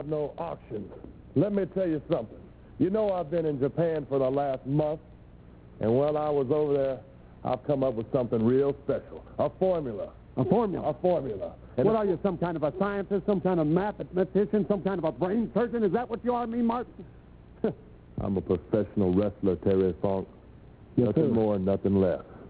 The interviewer is none other than Terry Funk and yes, he really was a baby here folks!
He sounds NOTHING like he does as the undertaker...i'll warn you all now.
First part of the interview most enjoyable part: I didnt get the first bit of the interview, this is a few sentences in before starting i love his quote in this one. shows you what he thought of himself then and maybe even now.